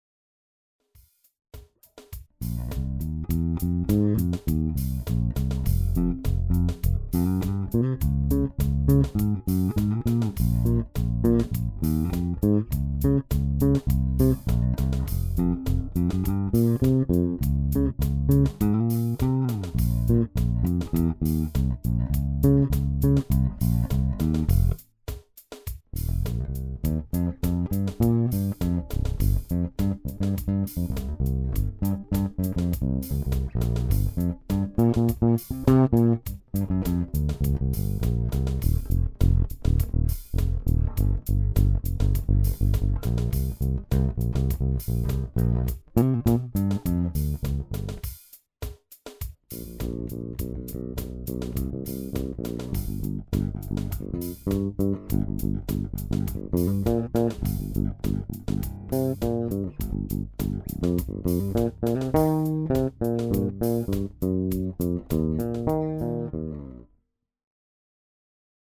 Mein 1978 Fender Jazz Bass...
Der Bass hat alles: Mojo, Narben, Dreck, Sound, Leben, unperfektion, ein bisl zu viel Gewicht(4,7KG), aber eine Dynamik....
80er Brigde und 77er Neck sind aktuell am besten, aber im Beispiel sind die True Vintage 74er zu hören-die zu 98% sich so anhören, wie die um ein vielfaches teurere originale